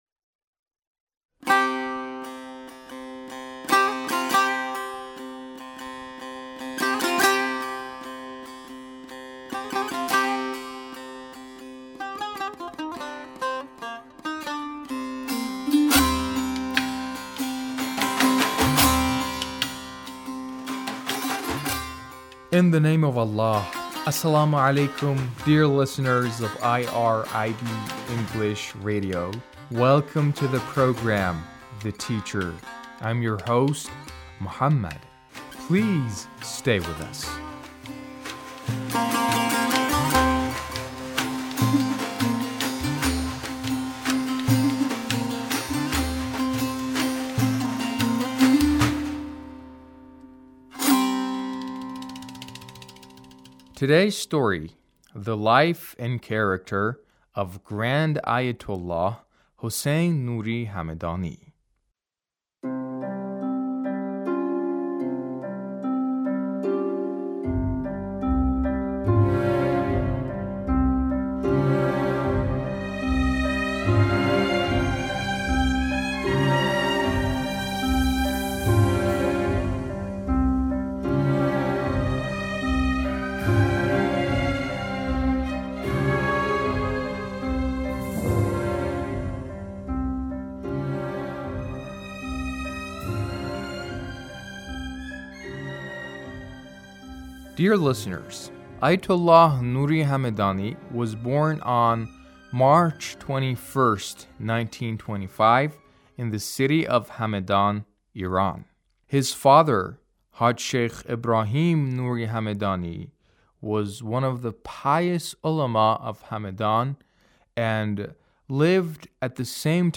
A radio documentary on the life of Ayatullah Nuri Hamedani